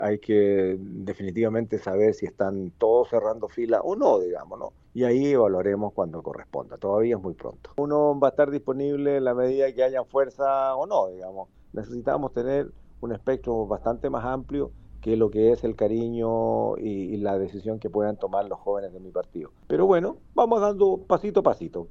Sin embargo, al ser consultado por La Radio, el senador Flores afirmó que estaría disponible para encabezar una campaña presidencial, aunque recalcó que es necesario tener un apoyo más amplio.